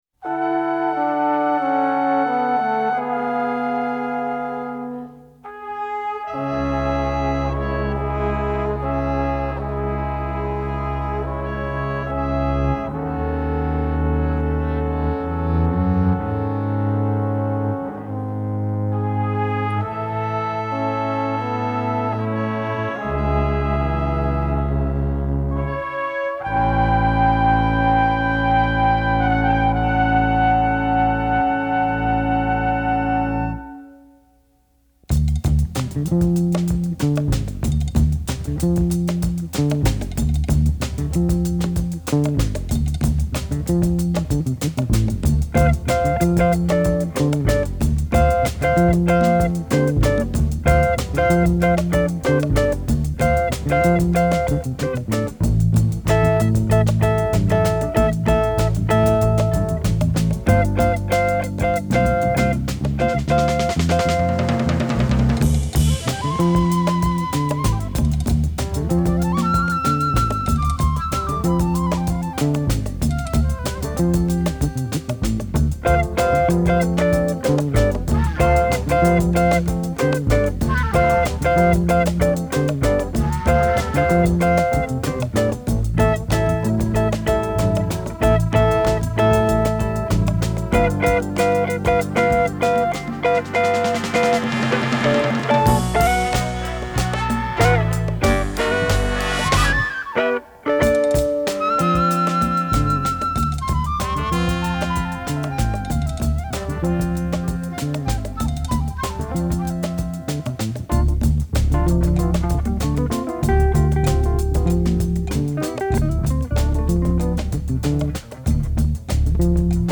Genre : Jazz